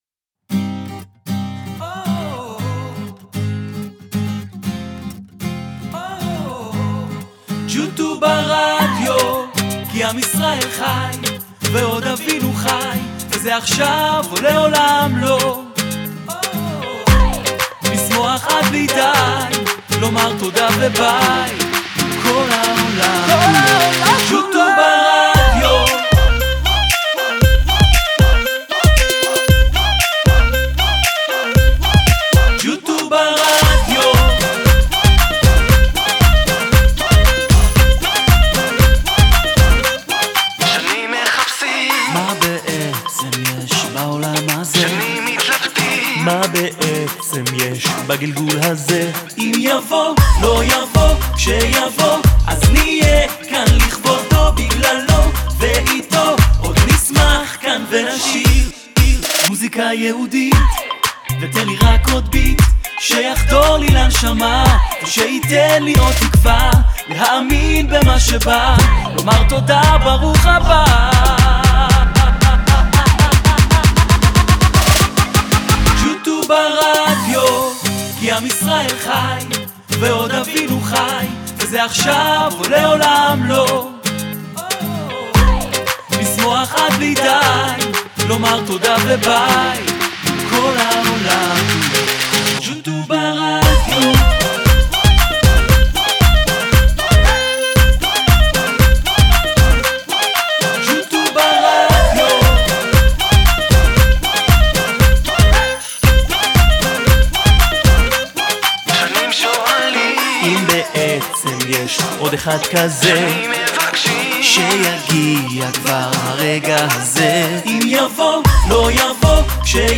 הרכב מוסיקה יהודית אלקטרונית